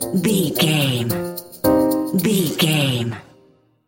Aeolian/Minor
percussion
flute
orchestra
piano
silly
circus
goofy
comical
cheerful
perky
Light hearted
quirky